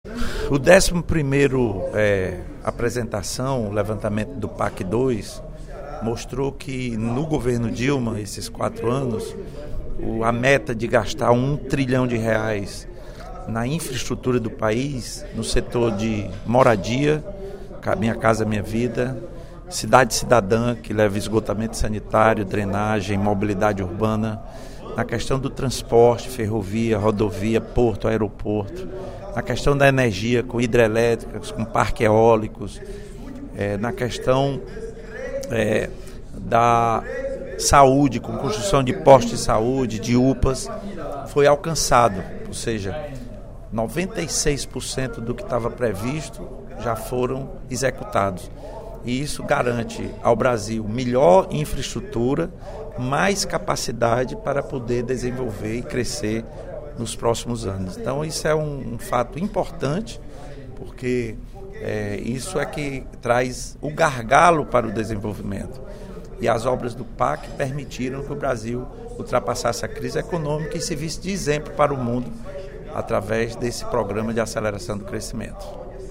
O deputado Lula Morais (PCdoB) afirmou, durante o primeiro expediente da sessão legislativa desta sexta-feira (12/12), que o Governo Federal, a partir da eleição do presidente Lula, tirou o País de uma posição falimentar, na qual por três vezes teve de recorrer ao FMI para retomar o crescimento do País.